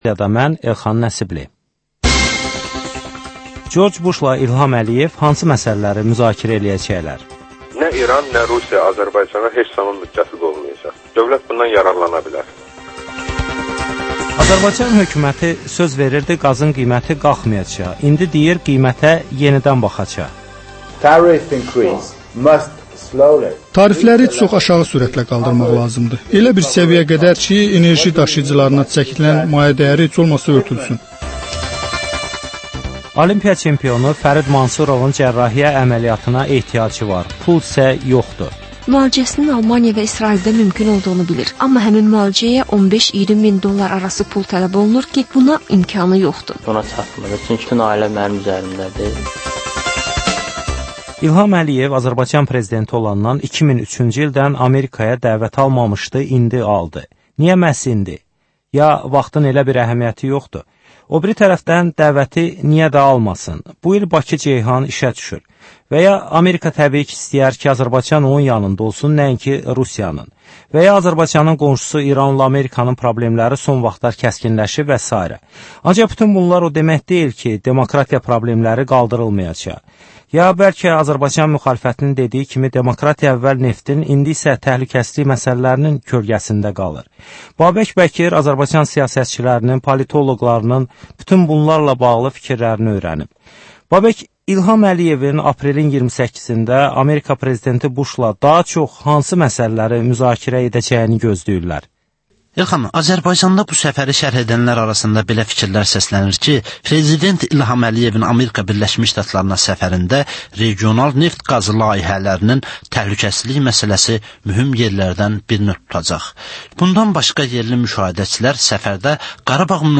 Müzakirə, təhlil, xüsusi reportajlar